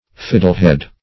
fiddlehead \fid"dle*head`\ n.